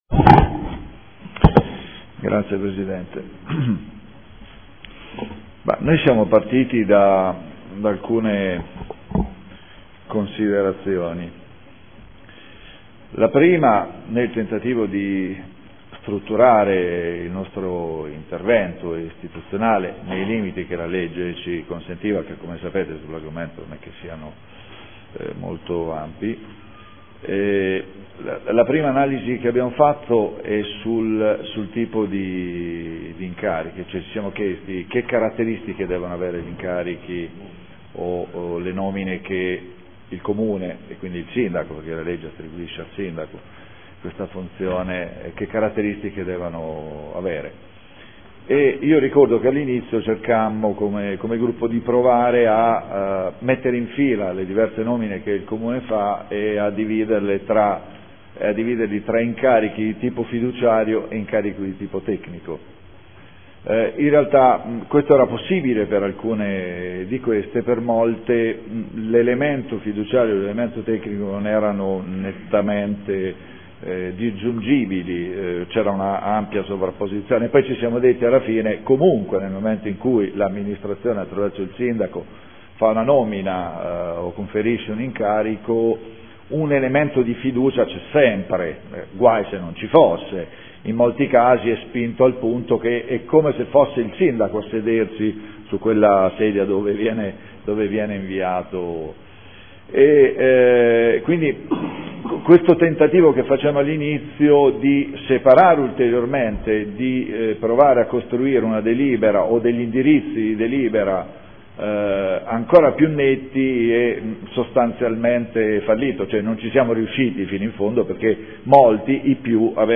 Seduta del 05/02/2015 Dibattito. Definizione degli indirizzi per la nomina e la designazione dei rappresentanti nel Comune presso Enti, Aziende, Istituzioni e Società partecipate